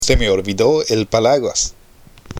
（セメ　オルビド　エル　パラグアス）